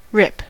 rip: Wikimedia Commons US English Pronunciations
En-us-rip.WAV